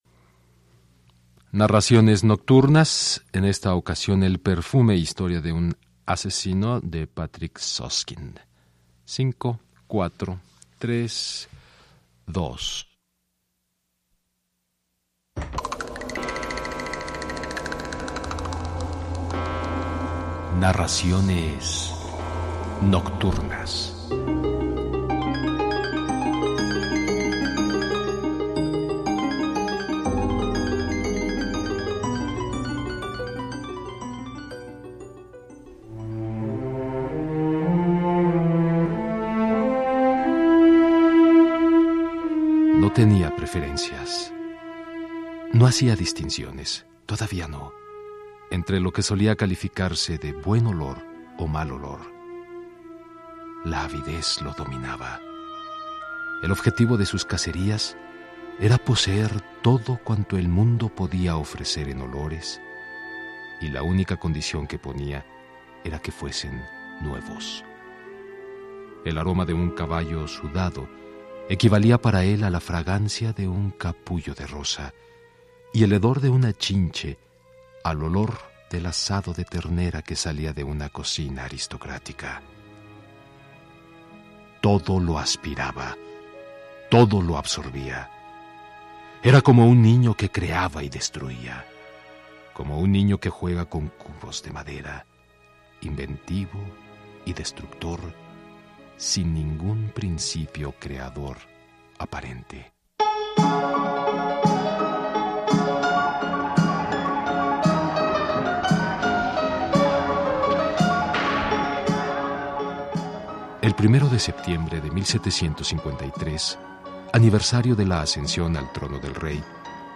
Narraciones nocturnas producción radiofónica de IMER abre las puertas a creaciones y adaptaciones literarias, dedicadas a establecer un contacto más estrecho con el público. Aborda diversos temas como el suspenso, la novela policiaca, el terror, la historia, la crónica, la ciencia ficción y el erotismo.